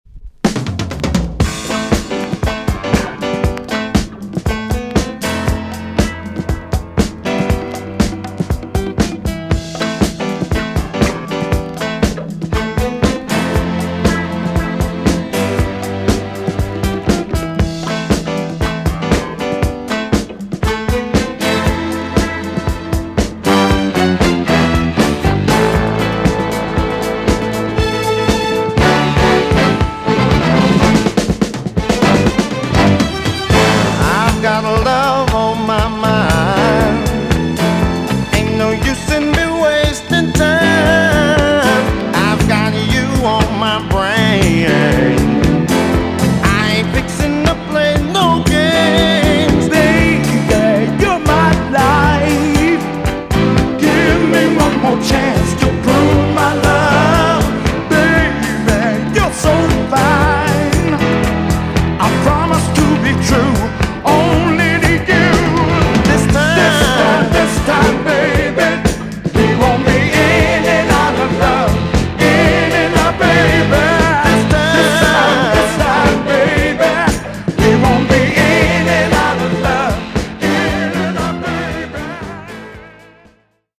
EX- 音はキレイです。
JAMAICAN SOUL RECOMMEND!!